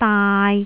注意「埗」和「蒲」這兩個字，雖然它們的聲母並不一樣，但是坊間拼法卻一樣是〔Po〕。